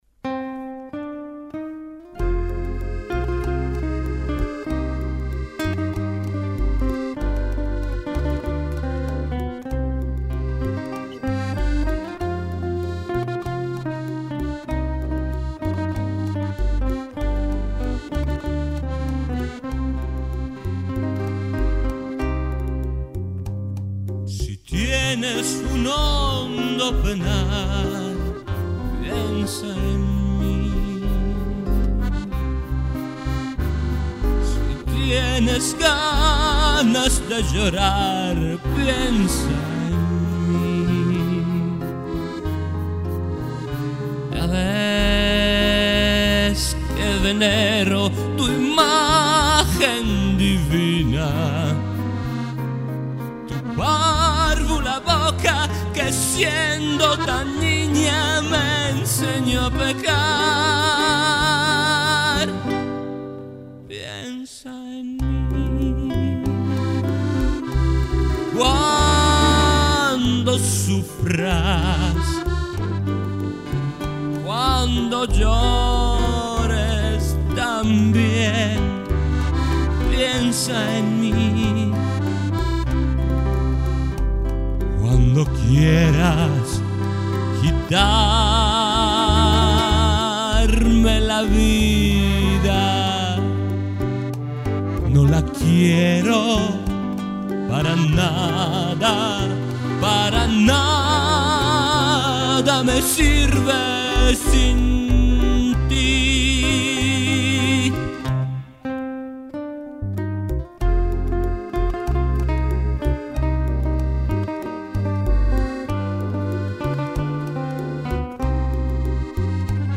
Cinematografica rumba-bolero
Cinematographic rumba-bolero